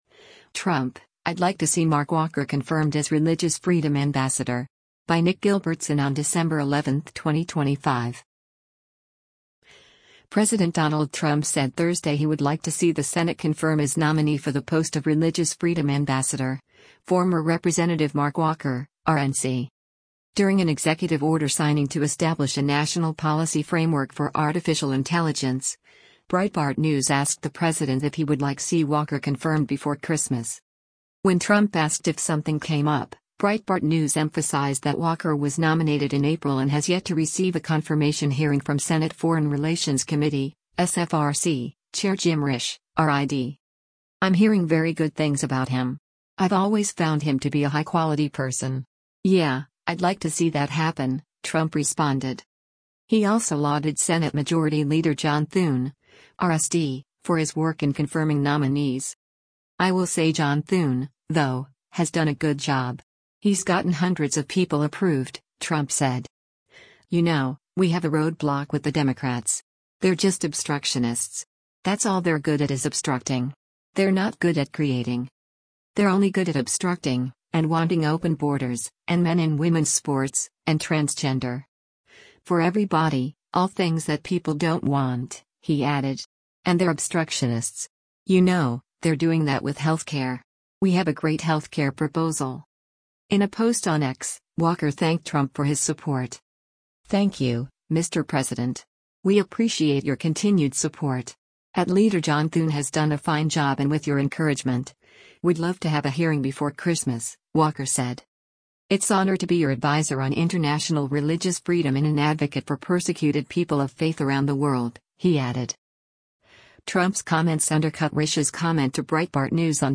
During an executive order signing to establish a national policy framework for artificial intelligence, Breitbart News asked the president if he would like see Walker confirmed before Christmas.